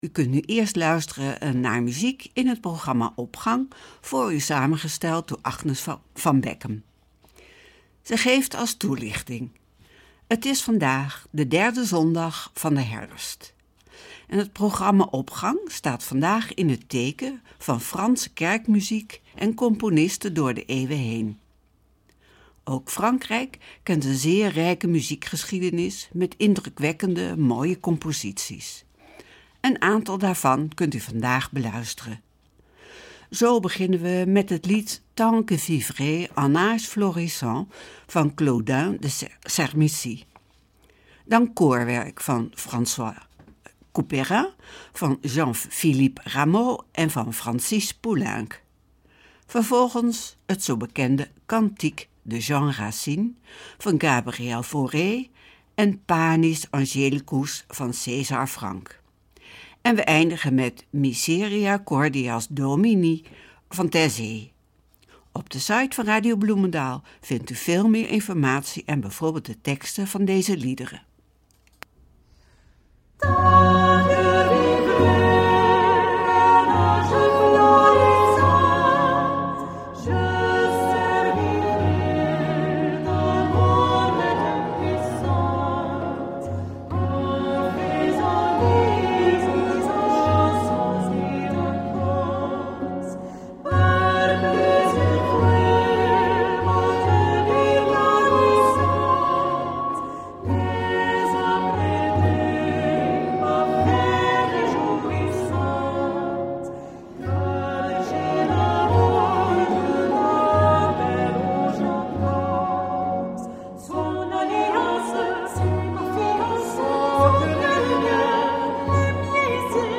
Opening van deze zondag met muziek, rechtstreeks vanuit onze studio.
De Opgang van vandaag staat in het teken van Franse kerkmuziek en componisten door de eeuwen heen.